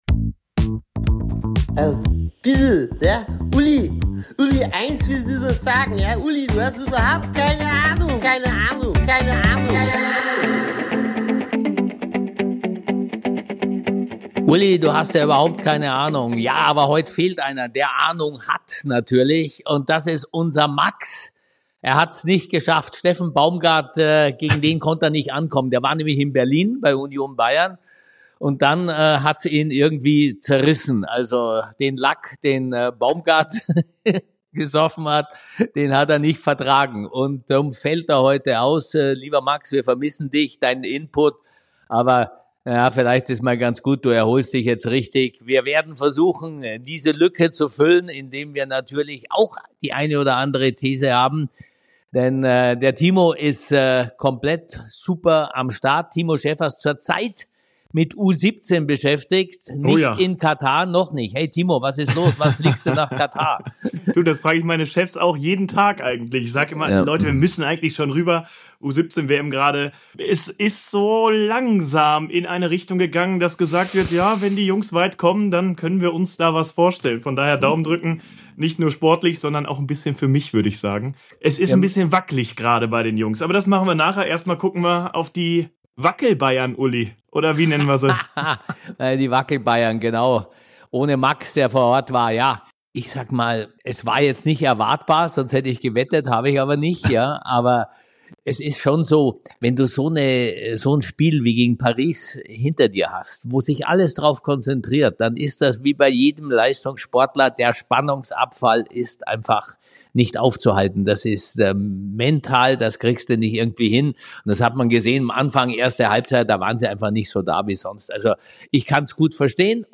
Auch zu zweit jede Menge drin, Glaskugel inklusive!